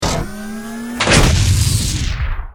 battlesuit_handcannon.ogg